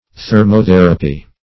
Thermotherapy \Ther`mo*ther"a*py\, n. [Thermo- + therapy.]